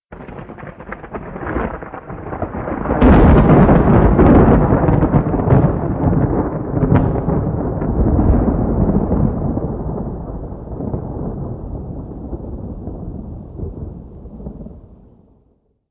Звуки грома, грозы
Звук мощной раскатистой грозы